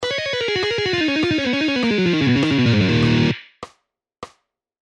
Legato Guitar Exercise
Lessons-Guitar-Mark-Tremonti-Legato-Exercises-1.mp3